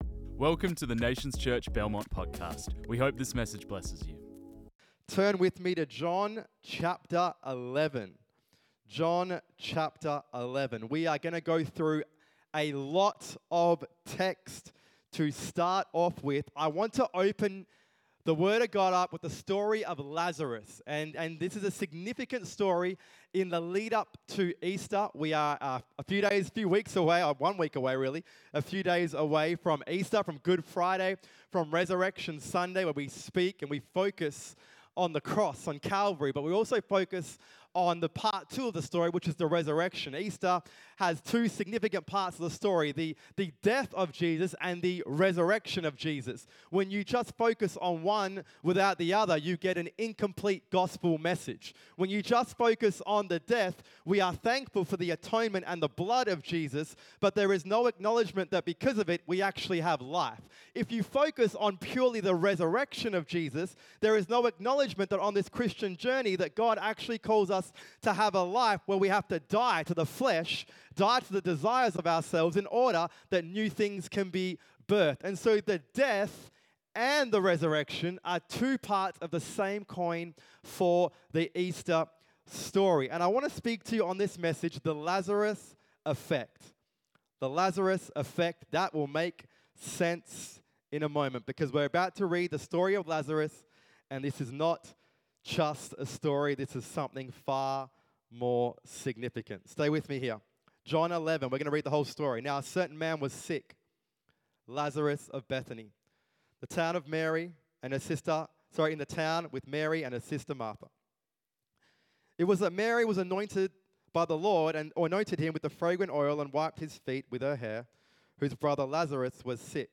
This message was preached on 13 April 2025.